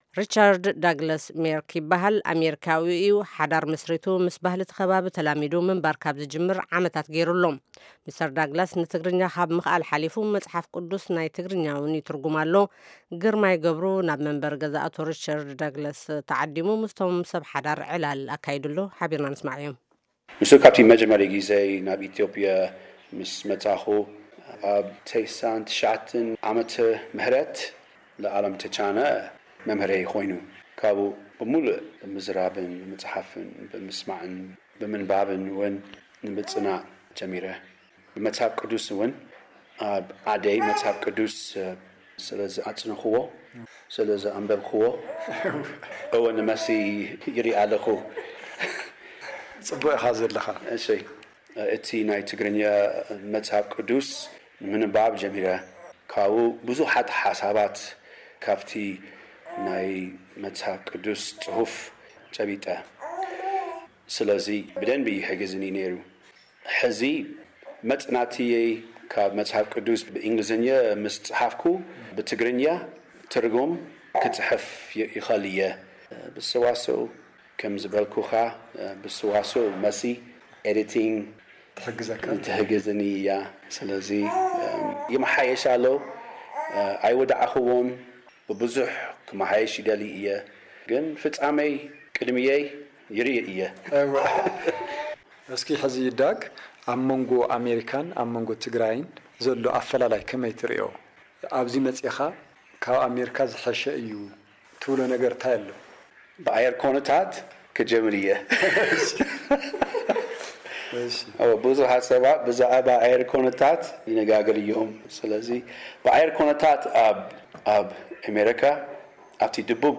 ንቃለ-ምልልስ